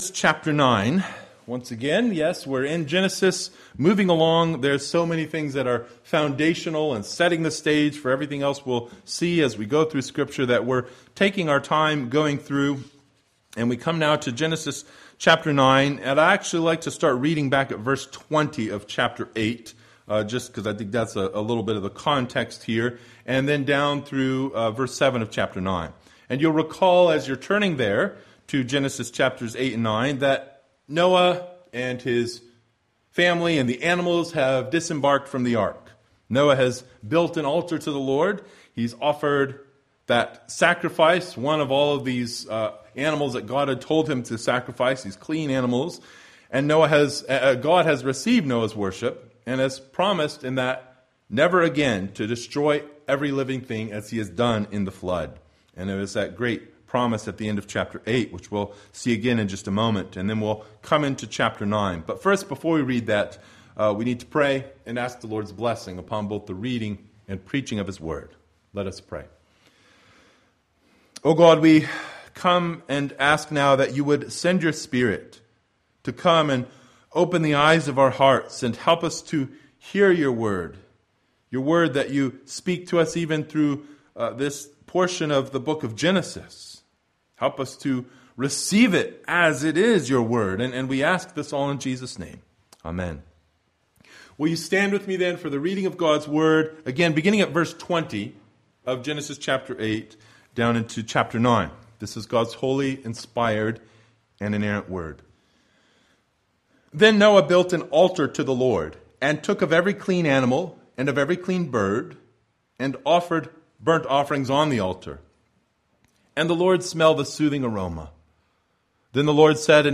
Passage: Genesis 8:20 - 9:7 Service Type: Sunday Morning